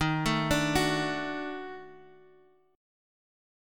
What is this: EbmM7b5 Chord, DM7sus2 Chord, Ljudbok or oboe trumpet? EbmM7b5 Chord